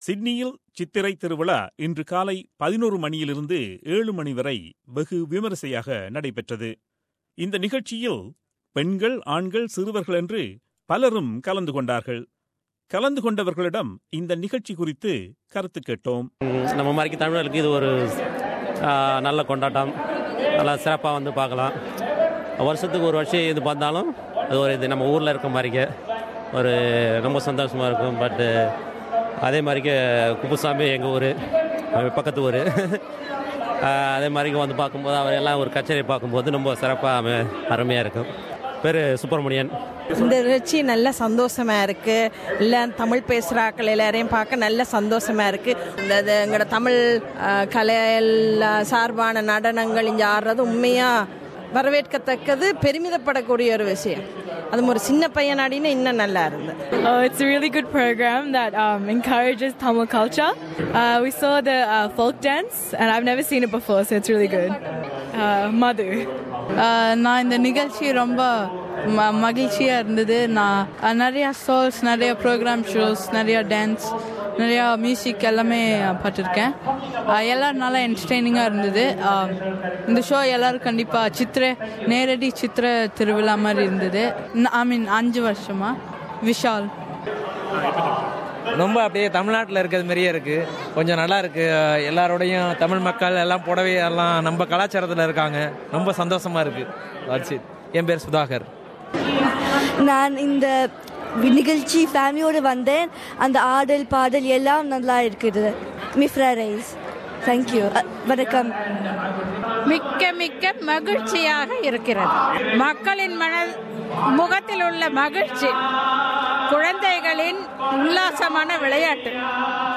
Chithirai Thiruvizha, A mega Tamil event organised by Tamil Arts and Culture Association was held in Sydney today.